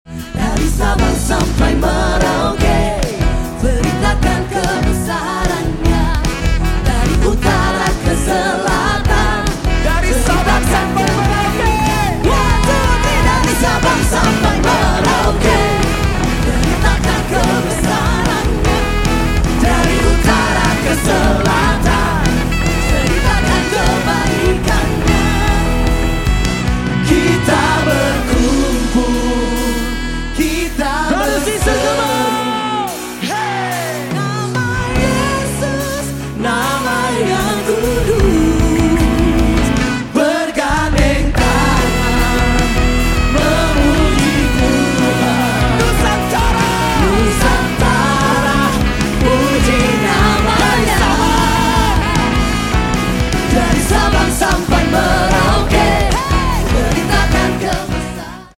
(Live at ADS 2025)